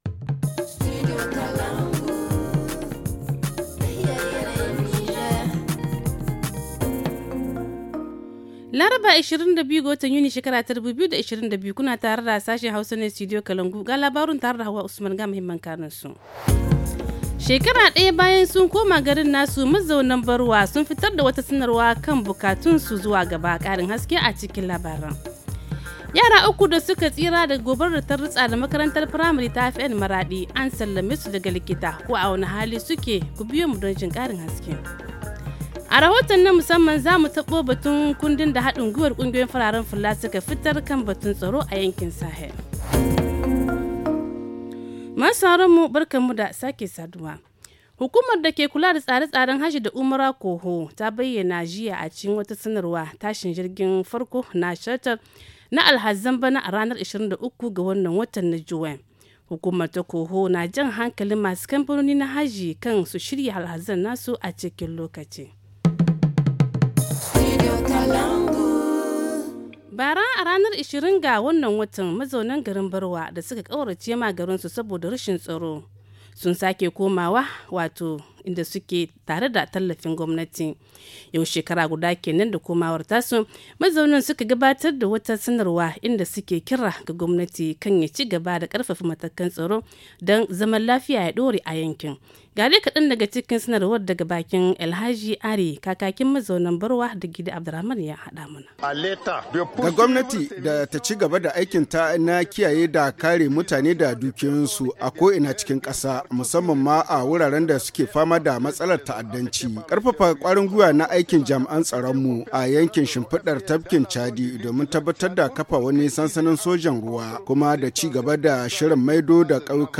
Le journal du 22 juin 2022 - Studio Kalangou - Au rythme du Niger